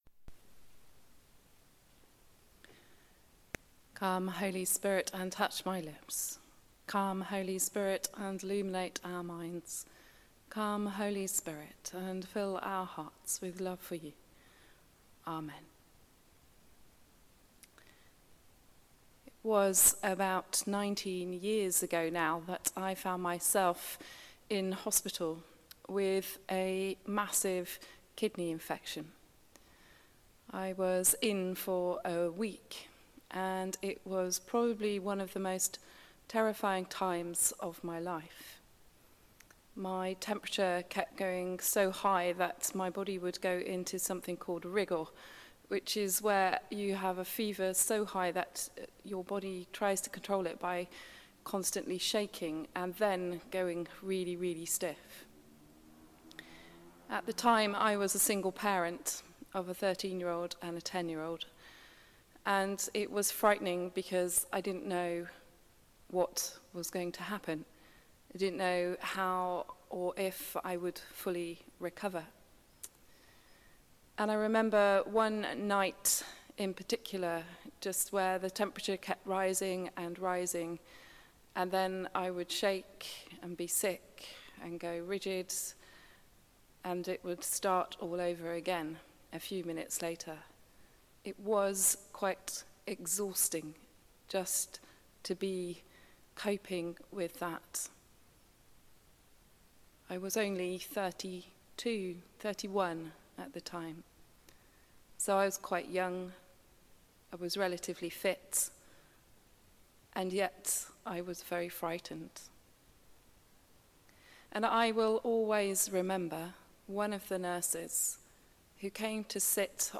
Sermon: The Mustard Seed | St Paul + St Stephen Gloucester